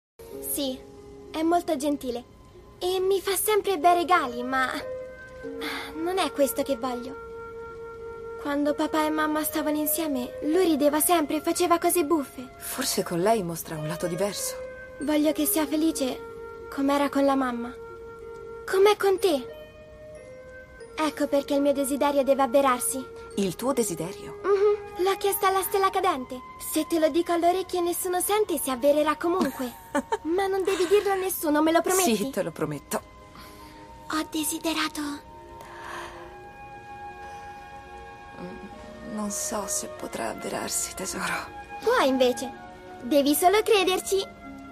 nel film TV "Amore nel castello di ghiaccio", in cui doppia Habree Larratt.